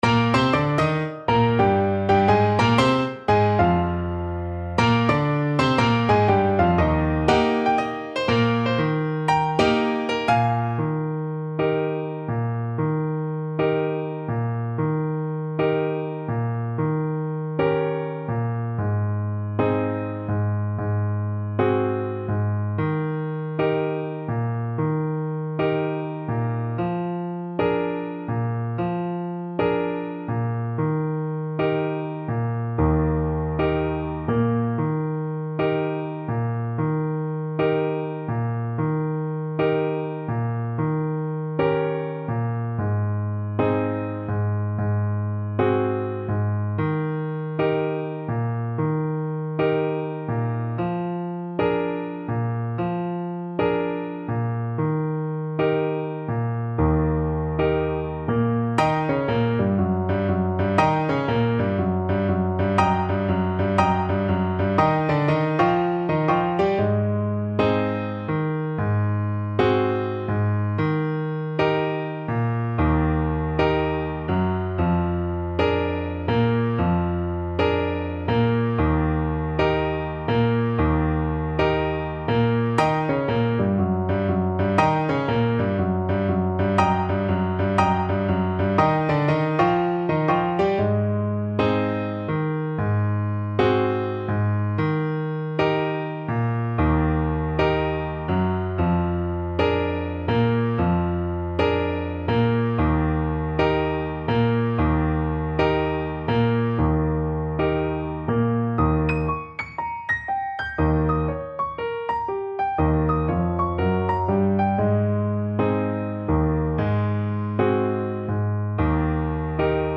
Moderato = 120
Jazz (View more Jazz Clarinet Music)